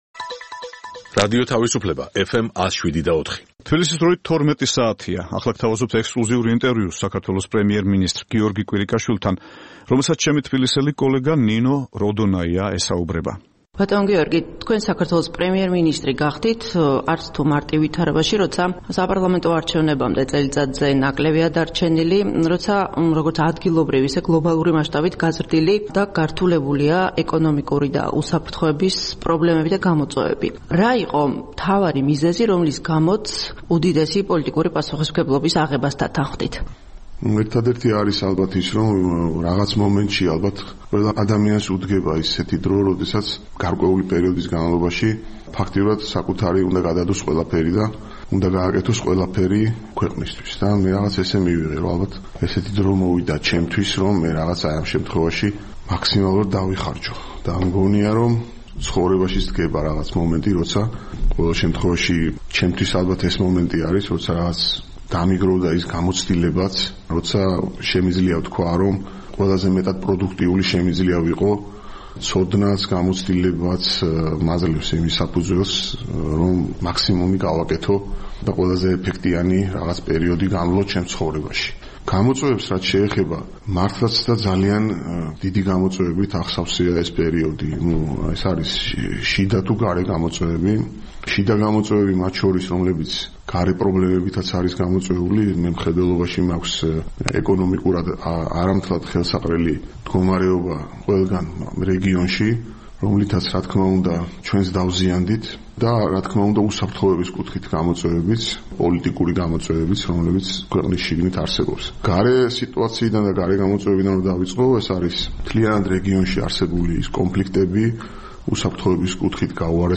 საქართველოს პრემიერ-მინისტრმა გიორგი კვირიკაშვილმა ექსკლუზიური ინტერვიუ მისცა რადიო თავისუფლებას.